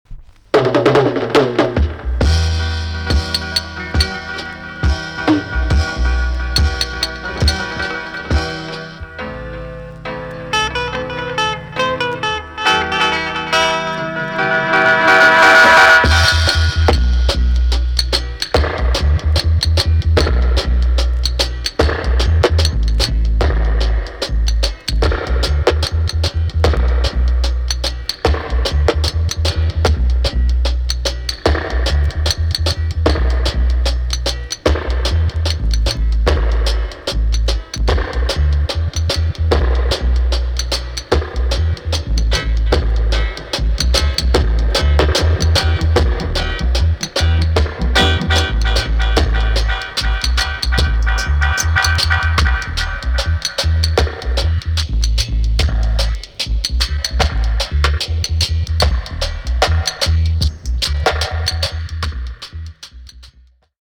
B.SIDE EX- 音はキレイです。
B.SIDEはVOCALなしのROOTS好きには堪らないよだれ物のDUB WISEな内容!!